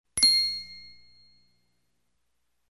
Звук внезапного озарения, или звук: «Идея!»
В этой части есть несколько колокольчиков.